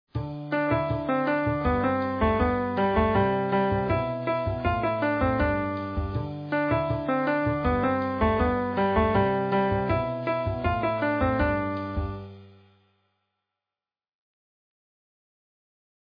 This next idea starts on beat two. It seems to bounce off the downbeat pitch of the bass. A simple sequenced line that really has a bit of a pop music feel.